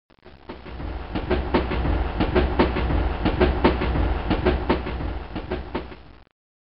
trainpass.mp3